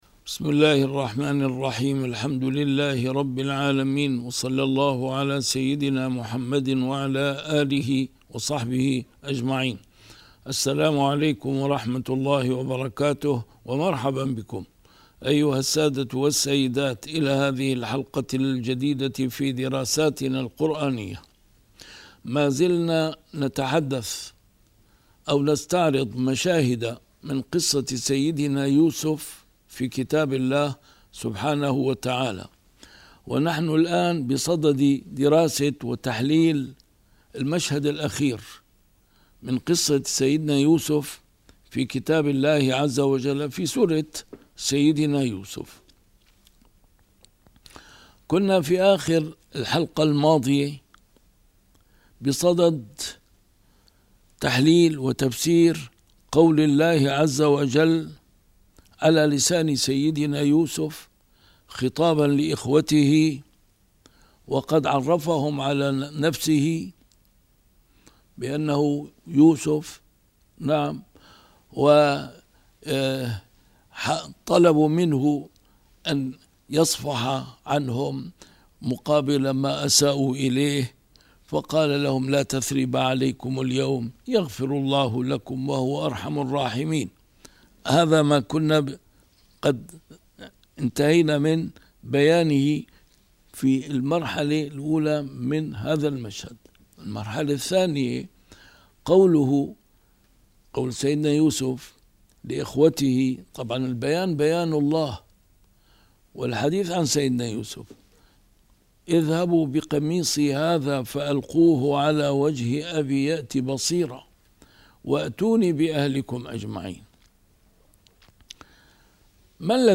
A MARTYR SCHOLAR: IMAM MUHAMMAD SAEED RAMADAN AL-BOUTI - الدروس العلمية - مشاهد من قصة سيدنا يوسف في القرآن الكريم - 10 - قميص سيدنا يوسف والإعجاز العلمي